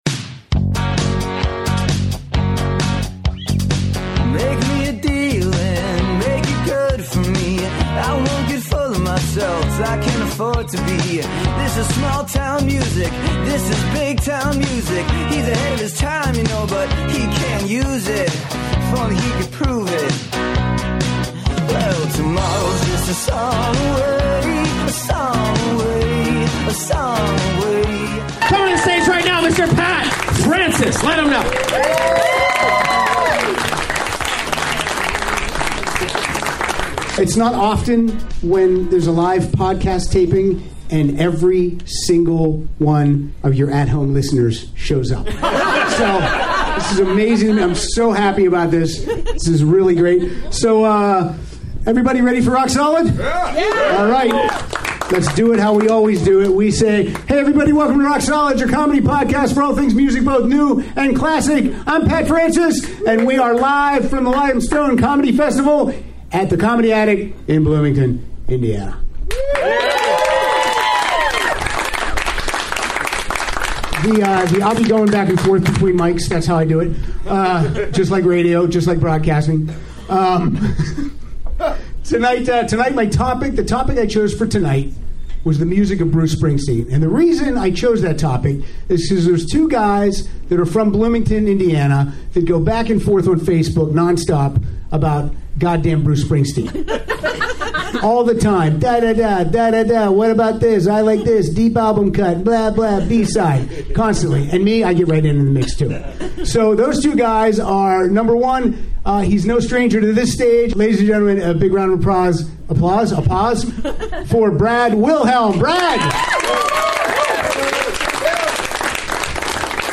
Recorded LIVE at The Comedy Attic as part of The Limestone Comedy Festival.